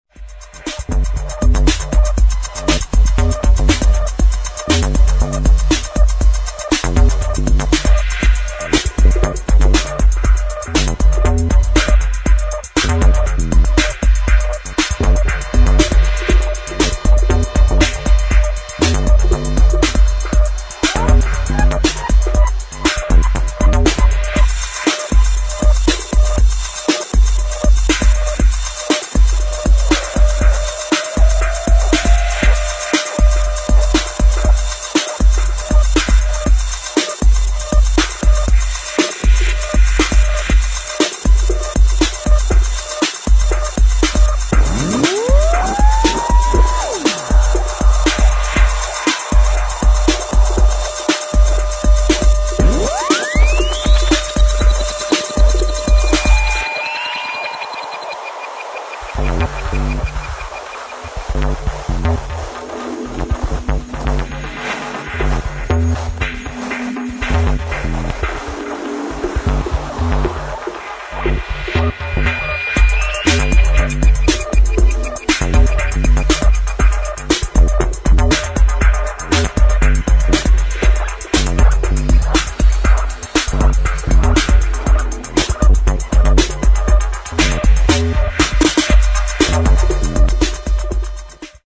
[ TECHNO | ELECTRO ]